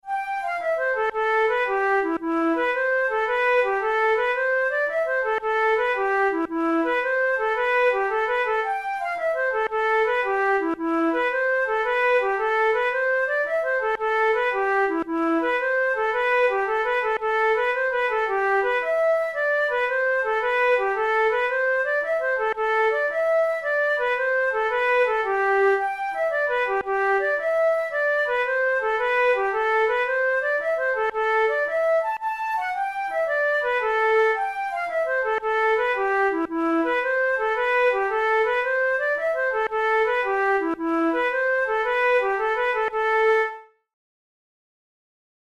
InstrumentationFlute solo
KeyA minor
Time signature6/8
Tempo112 BPM
Jigs, Traditional/Folk
Traditional Irish jig